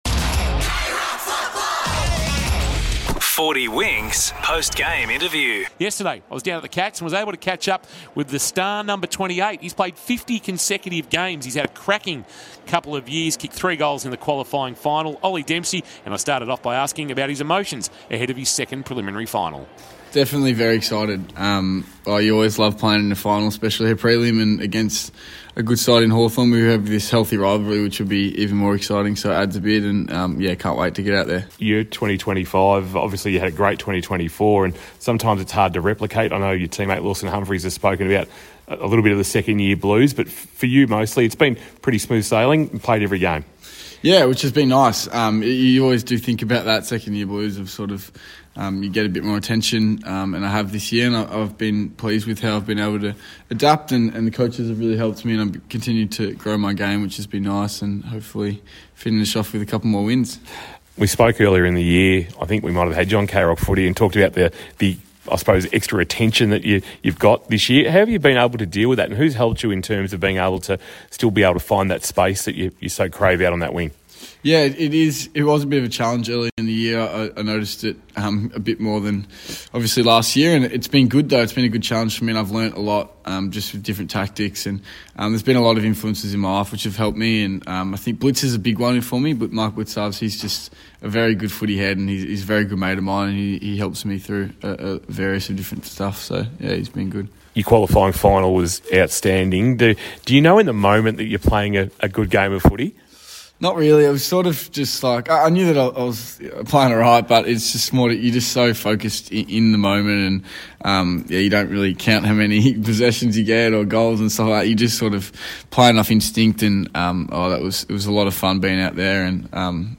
2025 - AFL - Preliminary Final - Geelong vs. Hawthorn - Pre-match interview